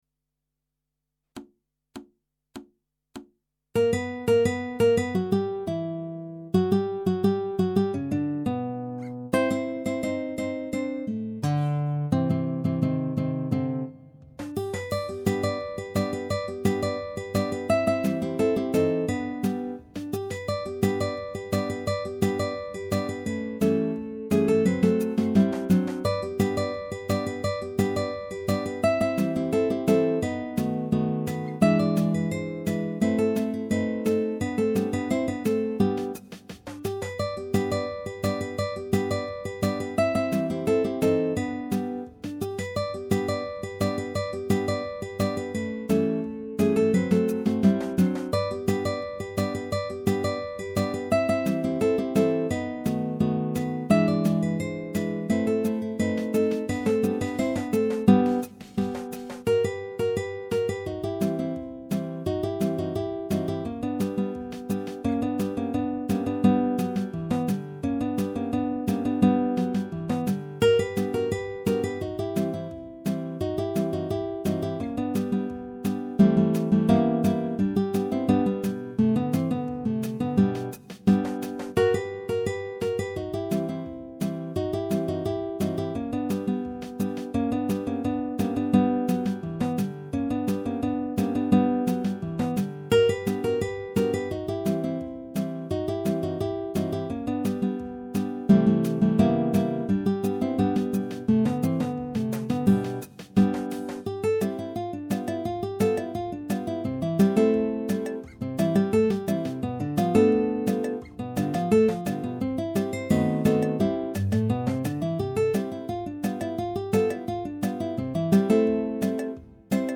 minus Guitar 4